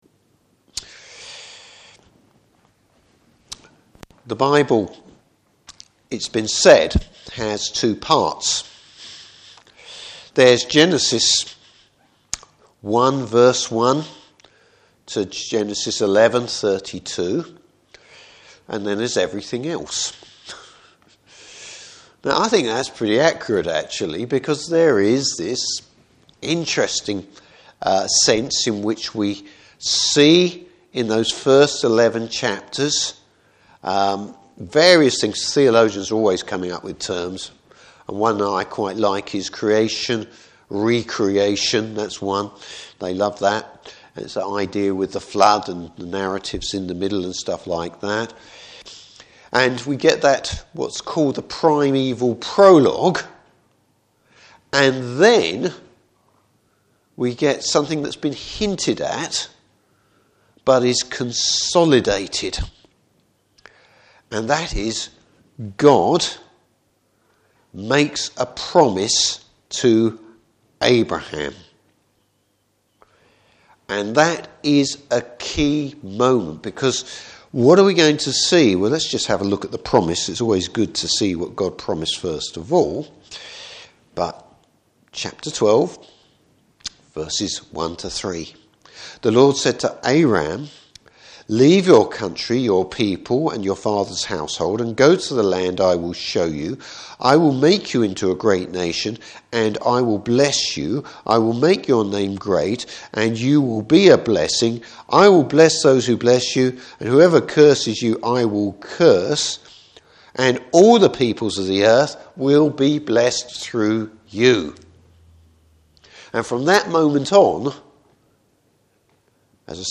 Service Type: Evening Service How God’s blessing is being realised.